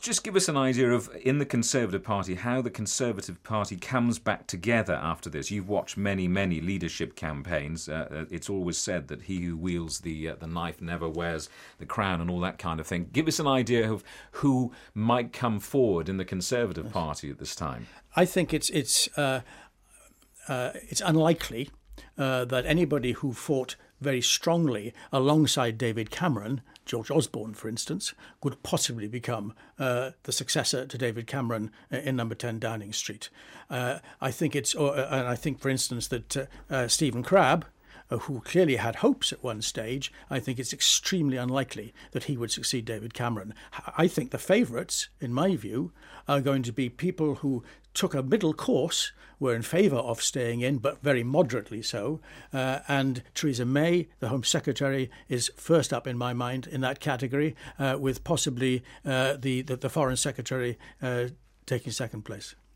Political commentator